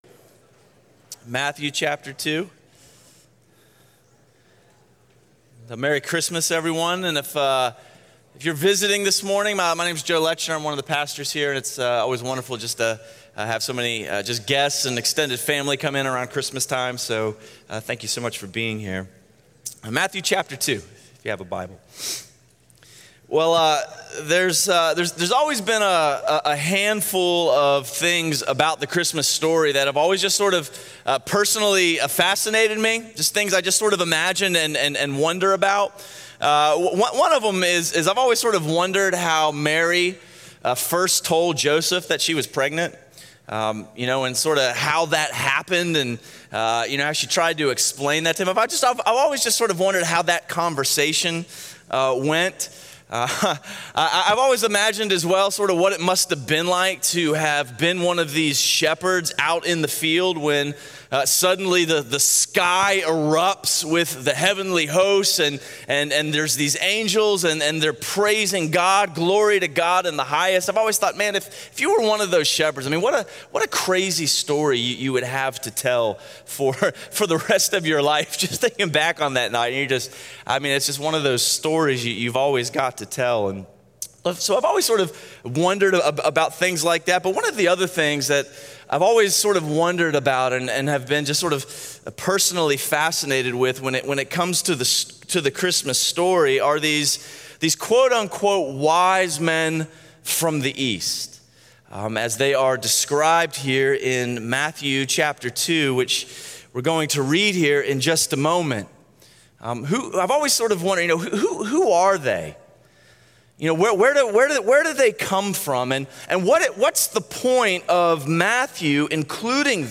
A Christmas message